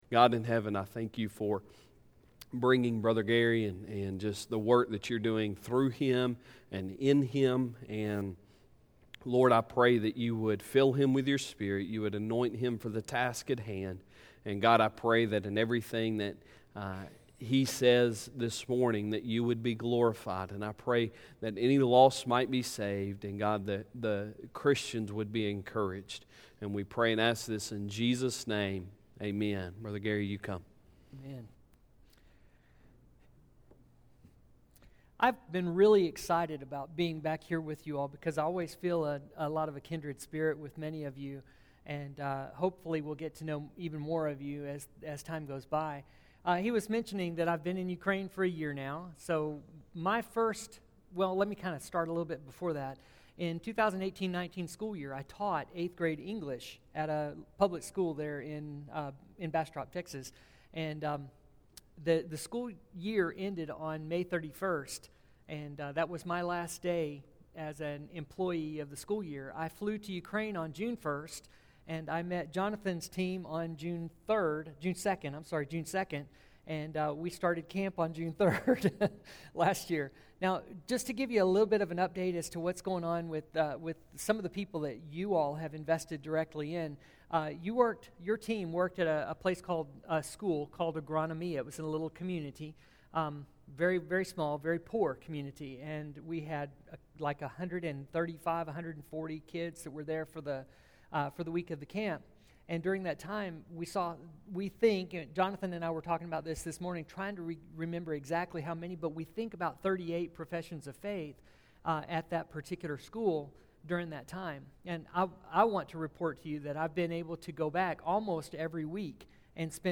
Sunday Sermon June 28, 2020